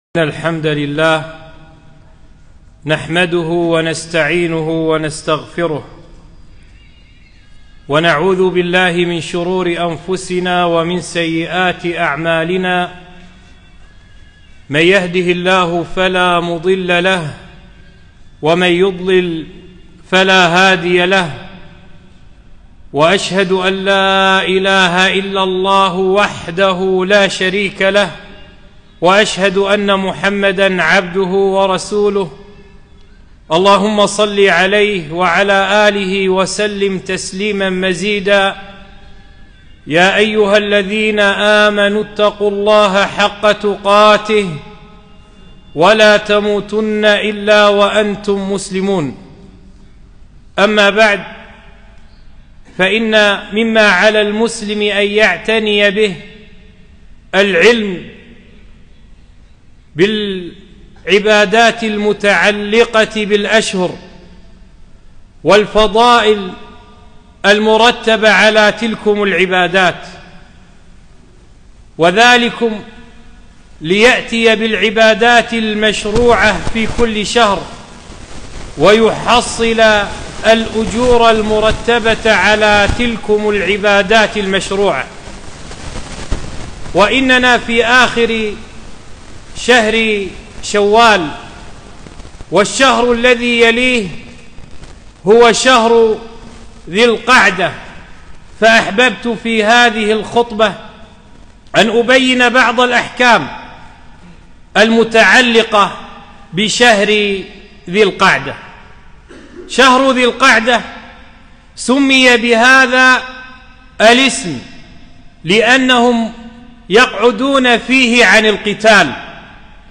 خطبة - شهر ذي الحجة فضائل وأحكام